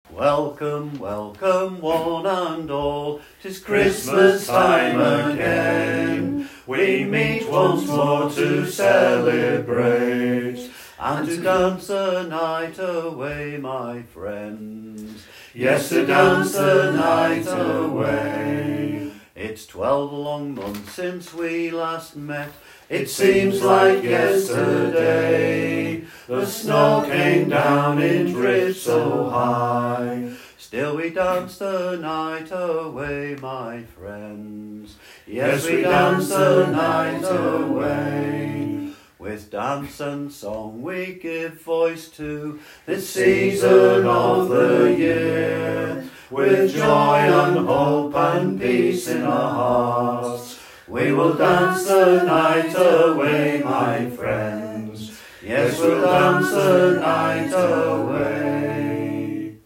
Tumbling Tom - Ceilidh Band
The song being sung in rehearsal can be heard